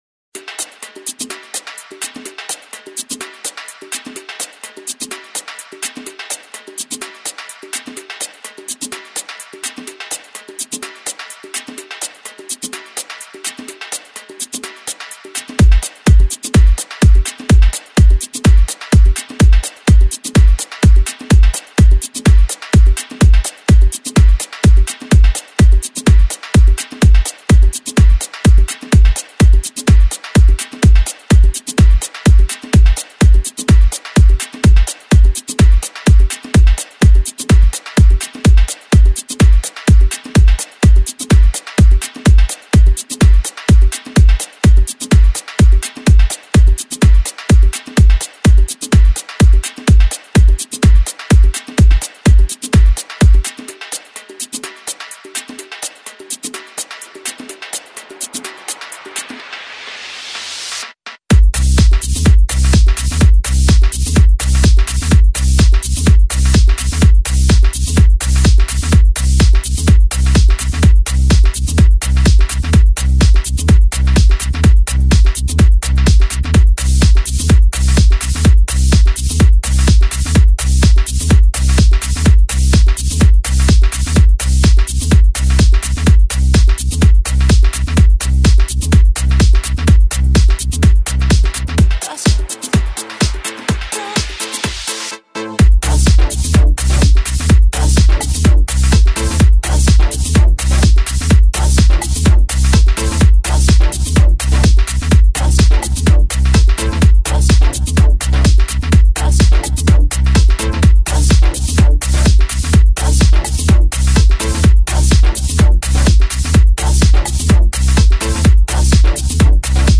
techno/house/progressive